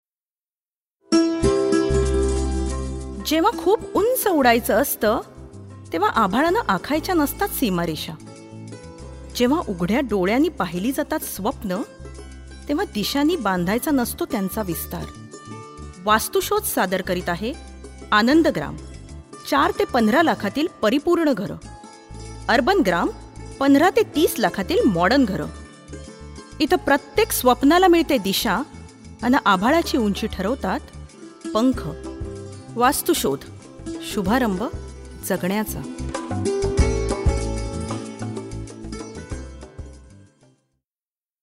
MA AV COM 01 Commercials Female Marathi